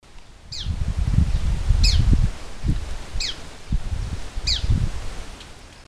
Bandurrita Chaqueña (Tarphonomus certhioides)
Nombre en inglés: Chaco Earthcreeper
Condición: Silvestre
Certeza: Observada, Vocalización Grabada